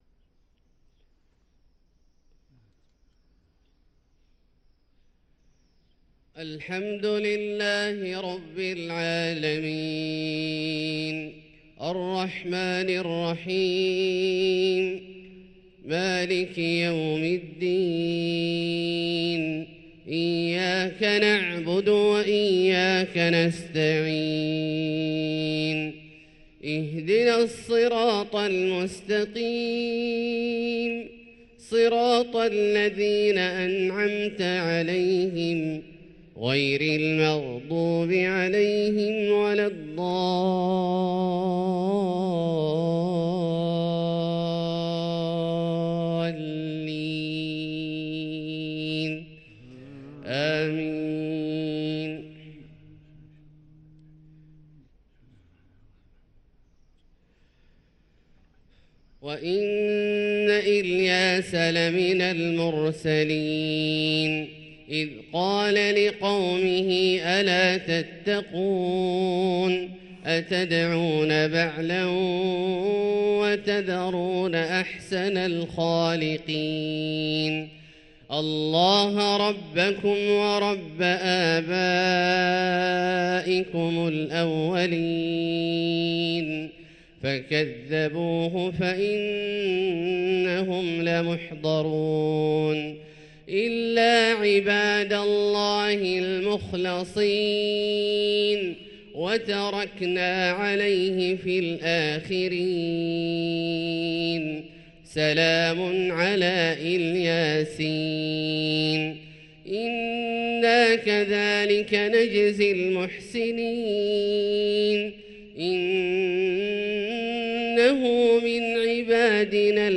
صلاة الفجر للقارئ عبدالله الجهني 3 ربيع الآخر 1445 هـ
تِلَاوَات الْحَرَمَيْن .